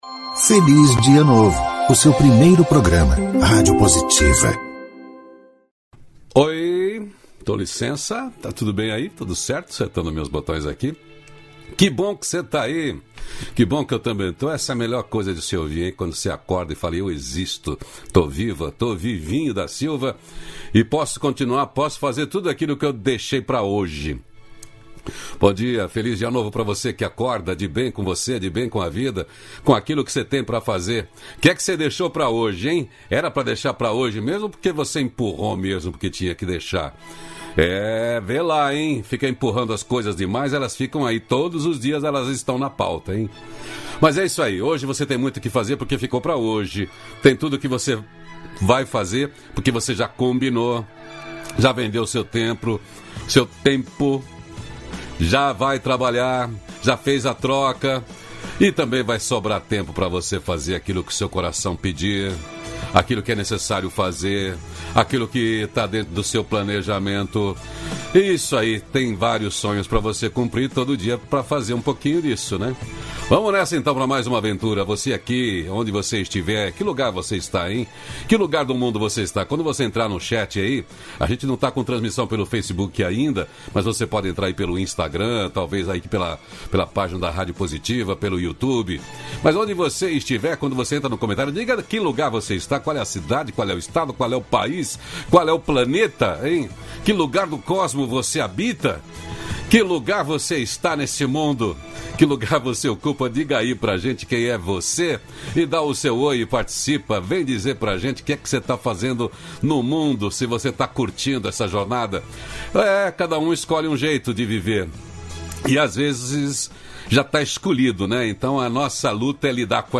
Diálogo nutritivo
Conexão direta com brasileiros que vivem na China. Vamos conversar com um casal brasileiro